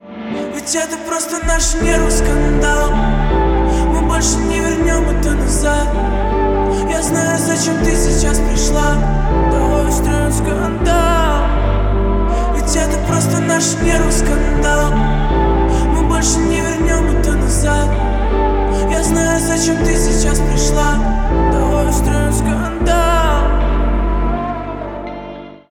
piano version